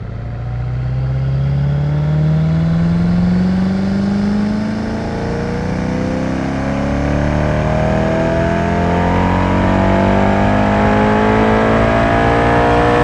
ttv8_03_accel.wav